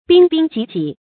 彬彬濟濟 注音： ㄅㄧㄣ ㄅㄧㄣ ㄐㄧˋ ㄐㄧˋ 讀音讀法： 意思解釋： 形容人才盛多的樣子。